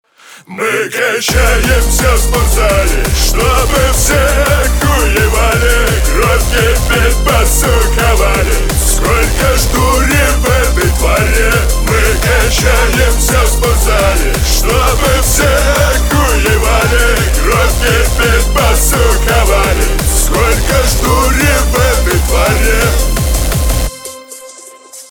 русский рэп
битовые , басы , качающие